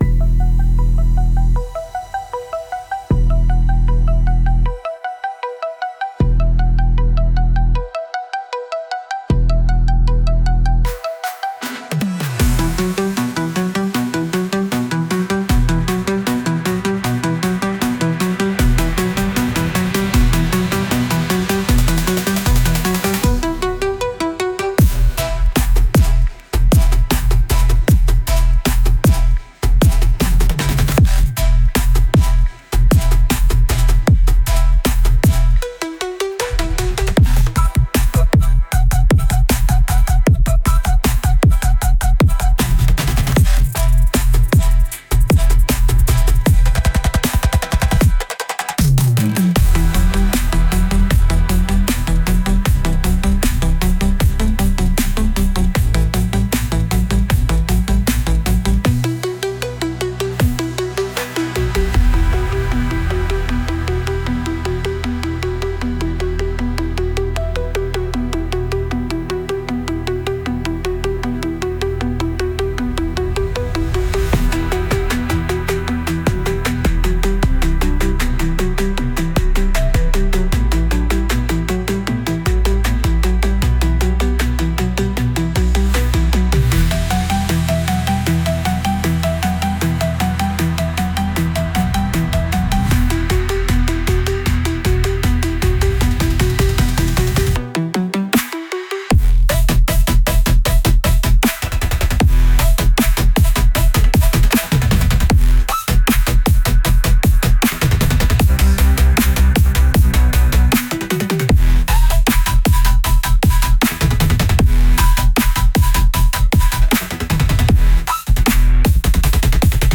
Genre: Trap Mood: Party Vibes Editor's Choice